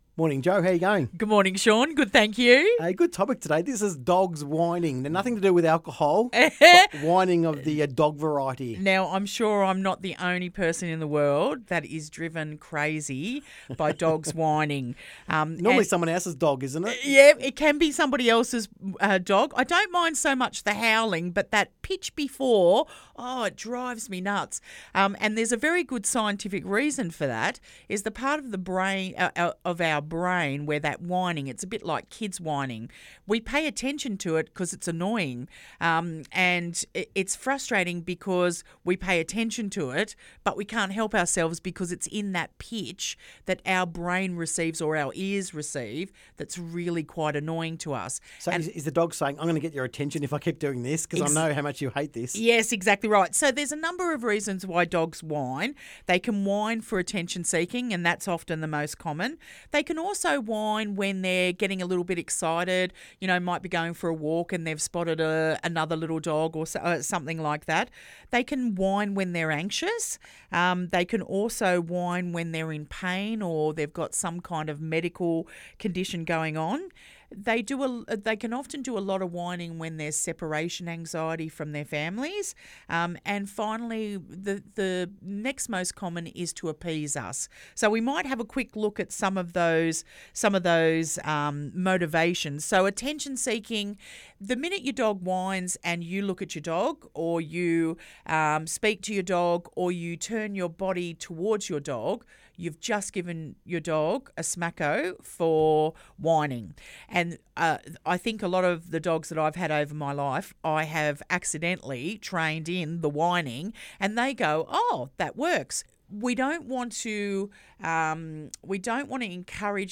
Dogs whining.